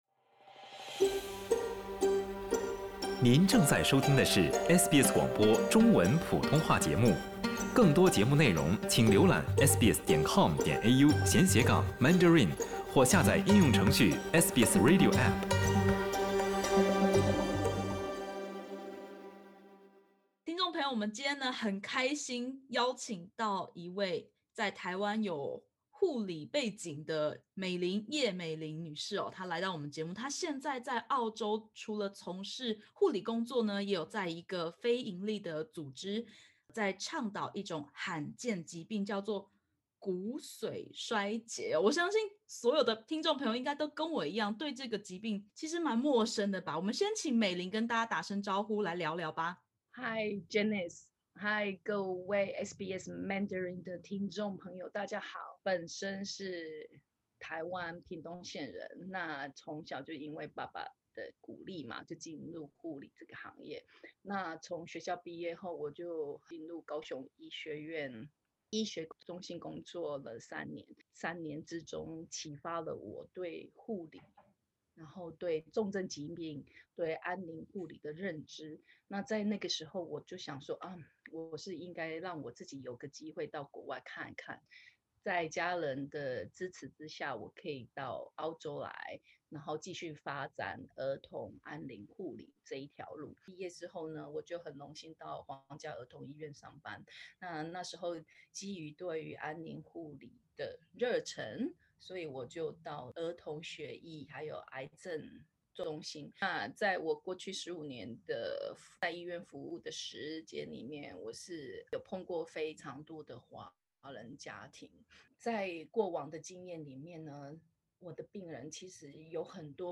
（点击首图收听采访音频）